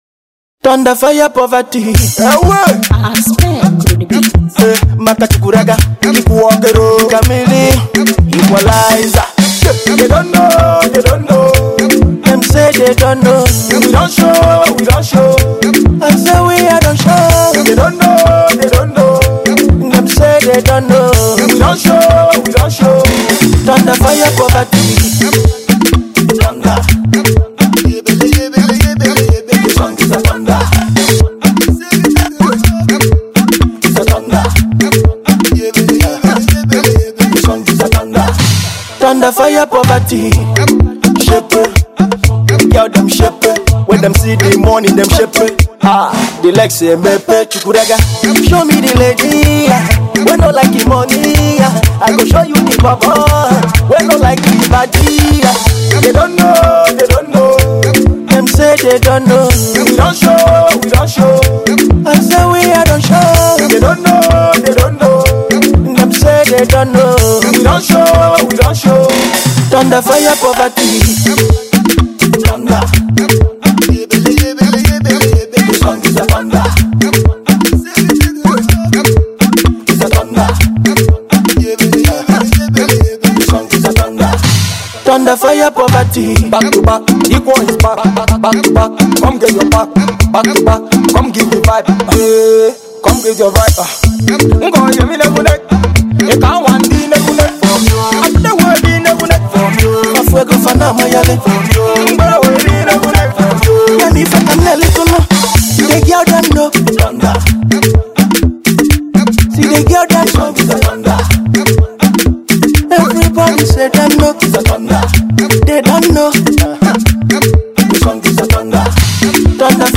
sparkling hot jam
groovy tune